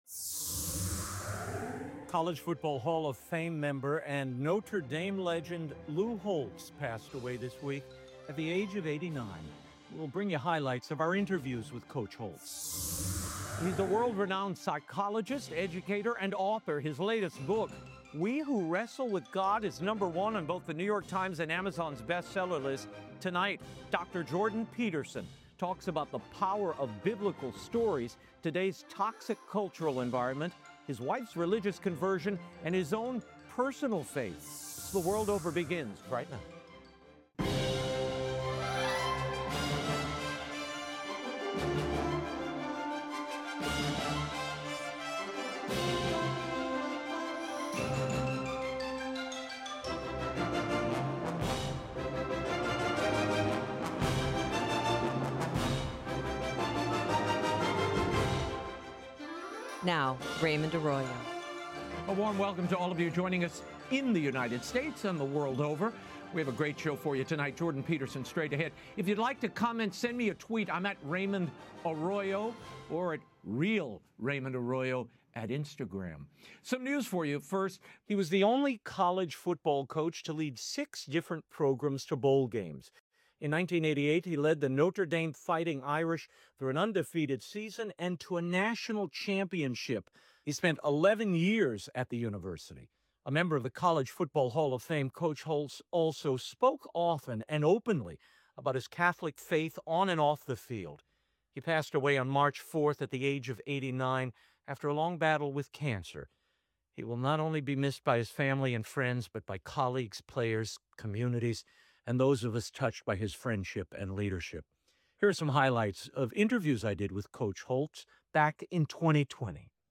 06 March 2026 Lou Holtz (1937-2026), Wrestling with God World Over 57 min About Highlights from interviews with the legendary College Football Hall of Fame member and Notre Dame coach, Lou Holtz, who passed away this week at the age of 89. And, the best of our 2024 interview with clinical psychologist and author Dr. Jordan Peterson on the power of Biblical stories, today’s toxic cultural environment, and his New York Times bestseller, We Who Wrestle with God.